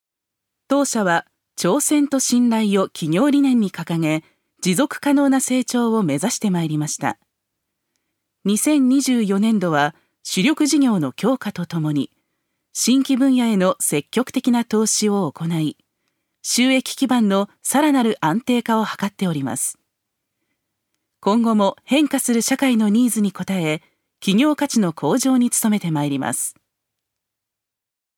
ナレーション２